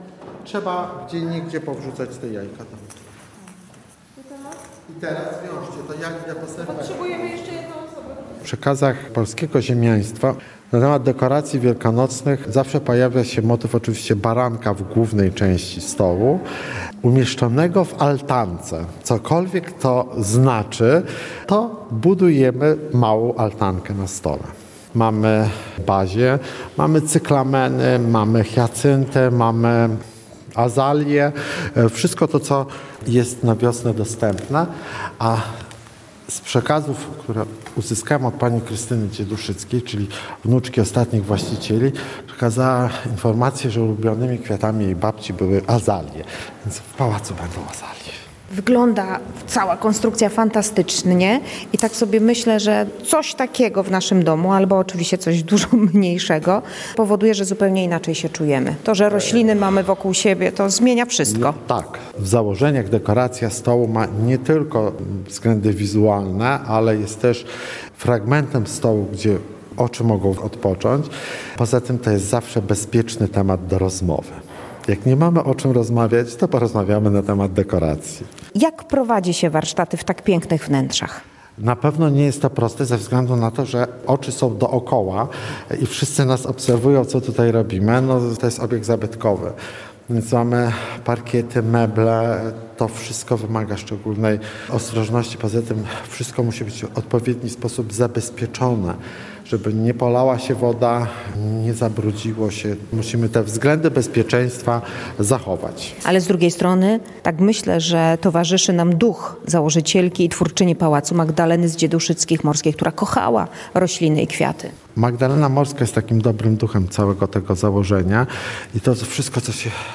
Relacje reporterskie • W Muzeum Dzieduszyckich w Zarzeczu można oglądać wielkanocne kompozycje kwiatowe inspirowane dawnymi tradycjami.